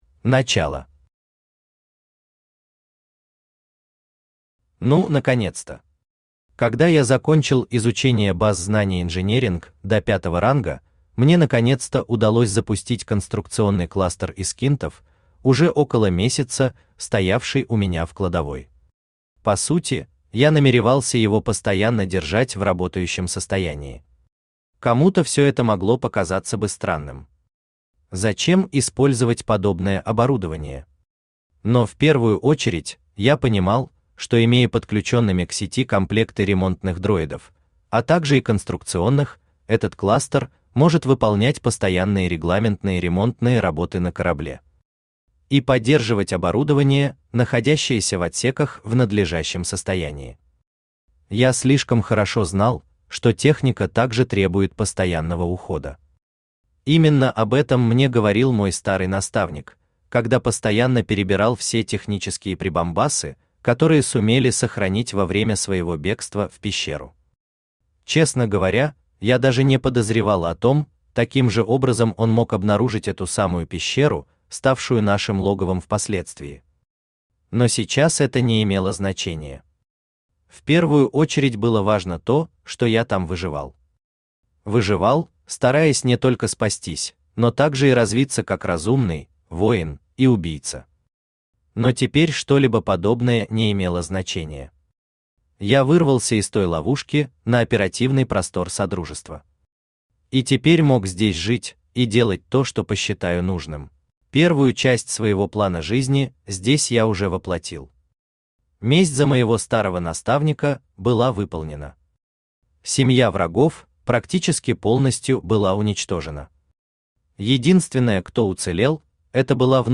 Аудиокнига Дикарь. Часть 8. Экспедиция | Библиотека аудиокниг
Экспедиция Автор Хайдарали Усманов Читает аудиокнигу Авточтец ЛитРес.